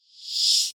Player ship has door with open/close sfx
space_door_close.wav